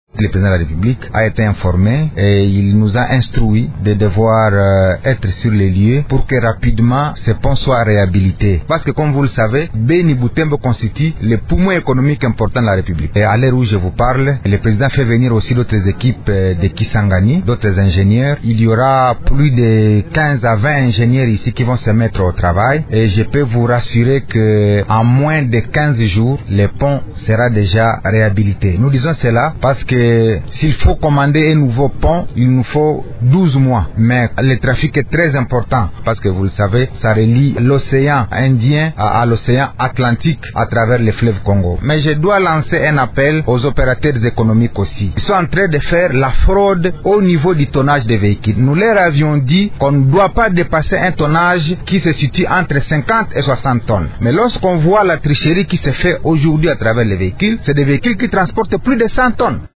Ecouter les explications de Julien Paluku.